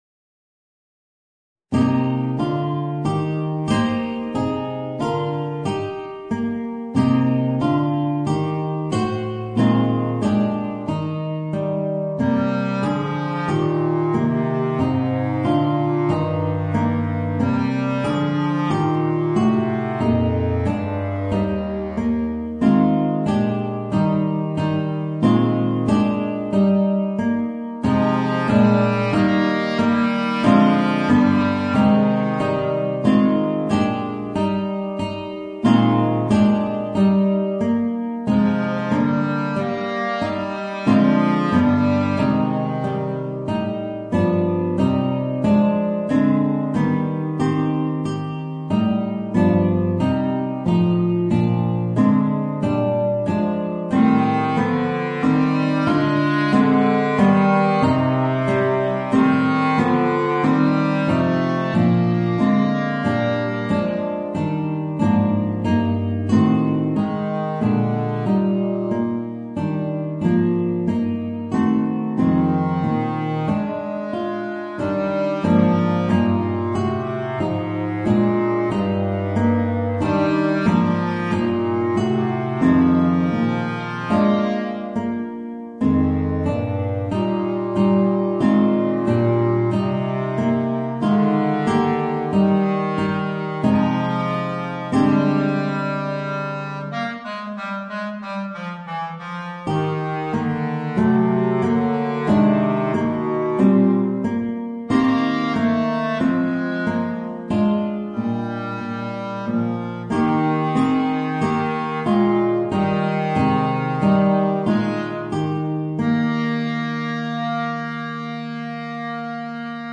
Voicing: Bass Clarinet and Guitar